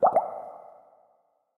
Minecraft Version Minecraft Version 25w18a Latest Release | Latest Snapshot 25w18a / assets / minecraft / sounds / block / bubble_column / upwards_ambient1.ogg Compare With Compare With Latest Release | Latest Snapshot
upwards_ambient1.ogg